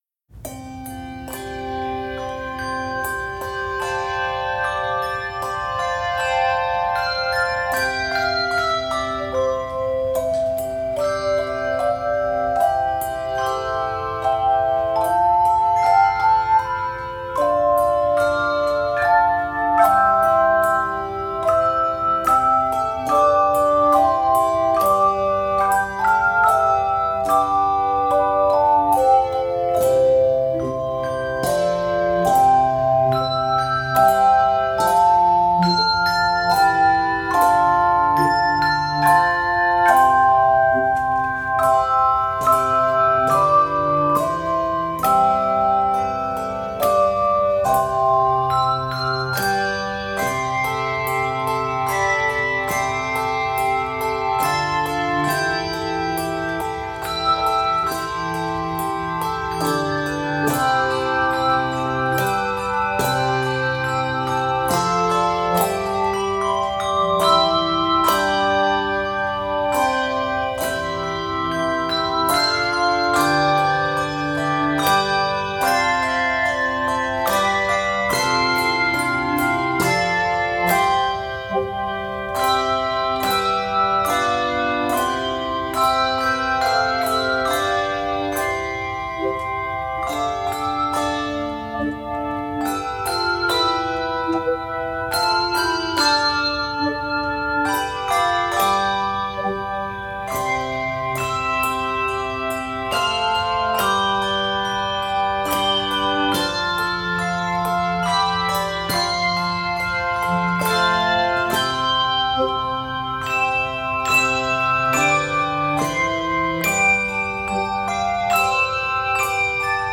Key of C Major.